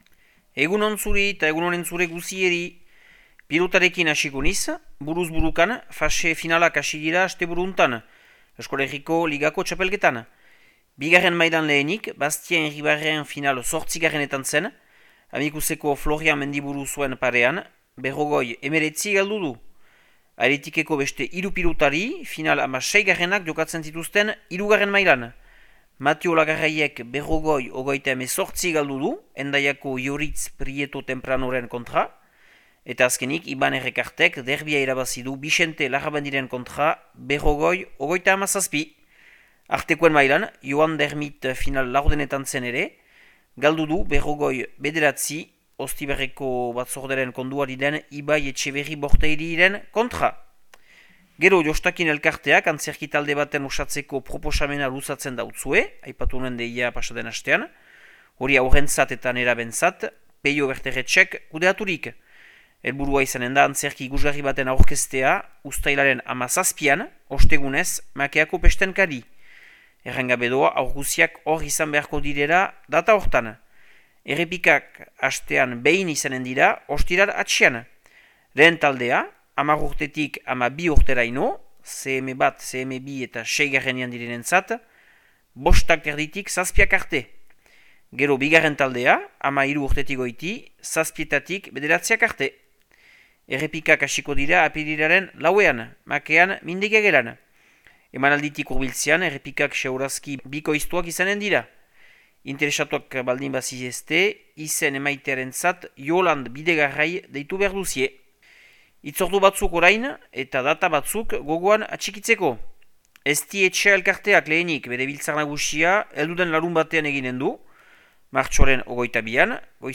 Martxoaren 17ko Makea eta Lekorneko berriak